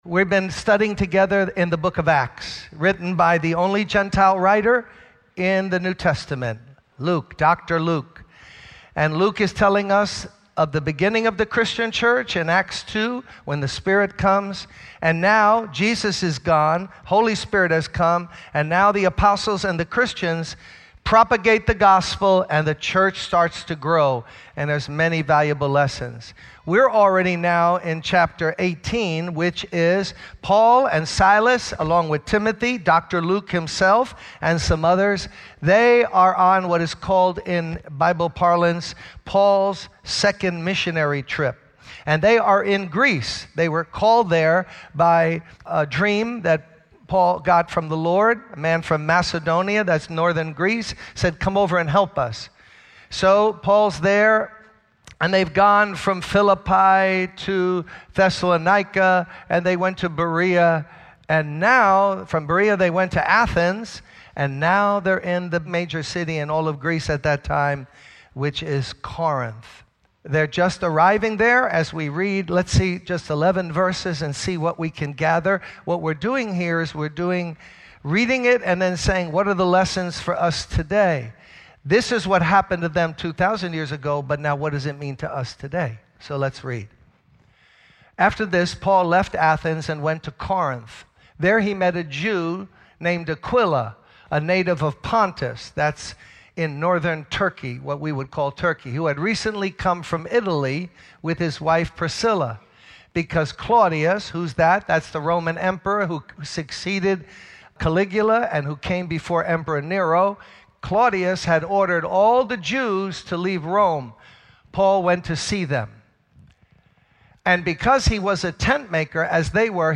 In this sermon, the preacher emphasizes the importance of not being afraid and continuing to talk about Jesus. He reminds the audience that the Holy Spirit is the ultimate teacher of the Bible and encourages them to pray for understanding.